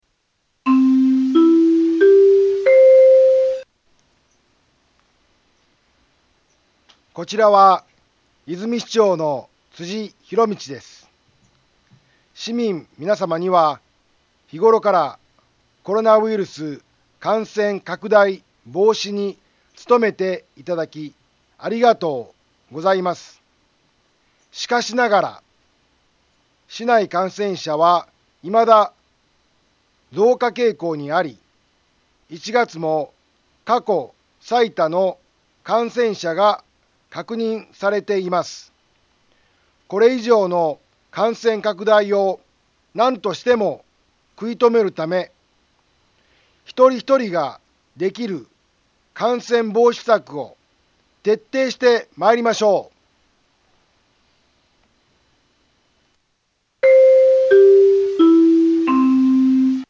Back Home 災害情報 音声放送 再生 災害情報 カテゴリ：通常放送 住所：大阪府和泉市府中町２丁目７−５ インフォメーション：こちらは、和泉市長の辻ひろみちです。